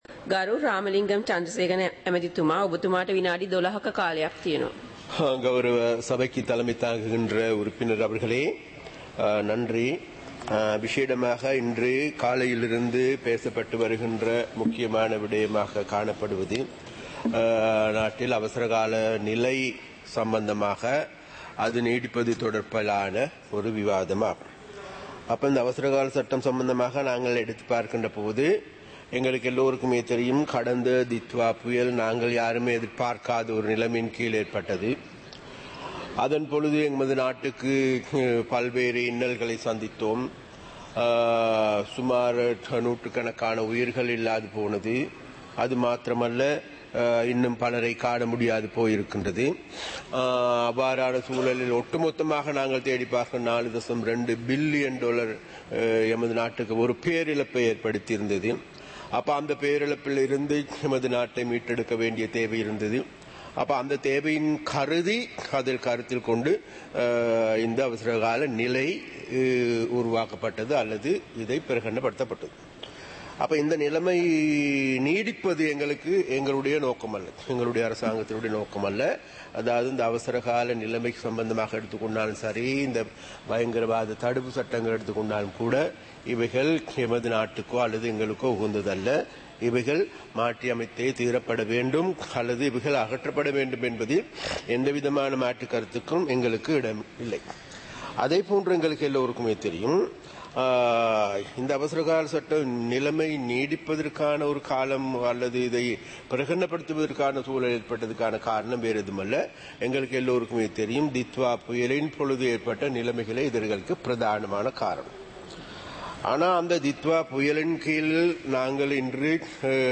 சபை நடவடிக்கைமுறை (2026-03-06)
நேரலை - பதிவுருத்தப்பட்ட